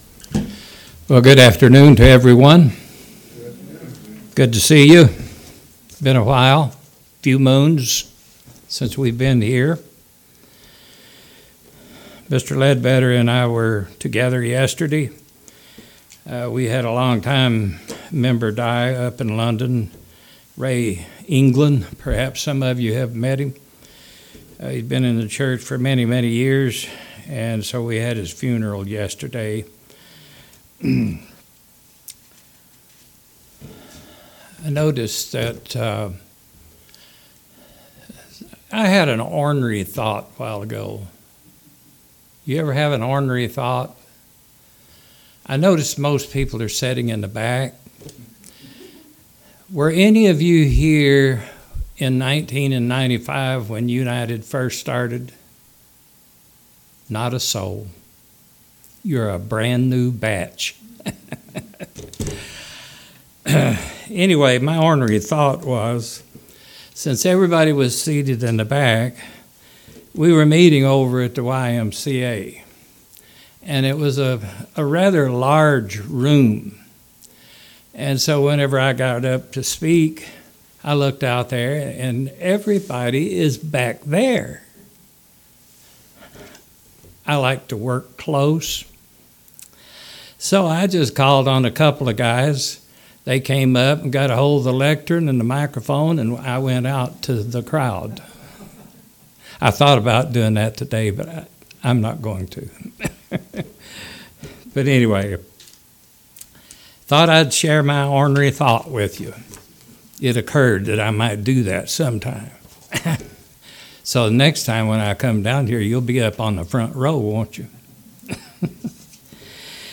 Given in Knoxville, TN